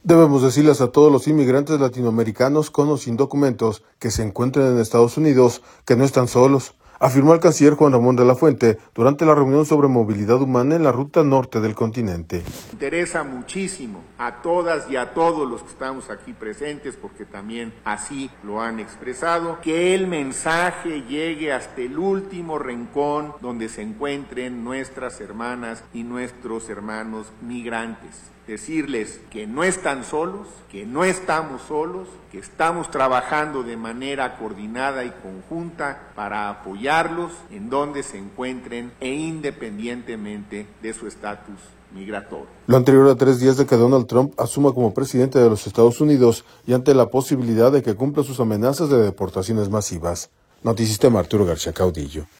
Debemos decirles a todos los inmigrantes latinoamericanos, con o sin documentos, que se encuentren en Estados Unidos, que no están solos, afirmó el canciller Juan Ramón De la Fuente, durante la Reunión sobre Movilidad Humana en la Ruta Norte del Continente.